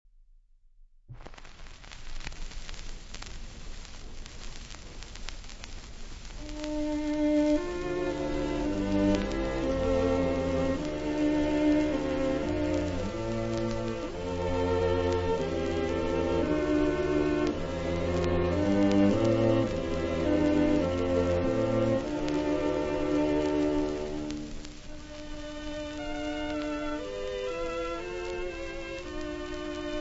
• Registrazione sonora musicale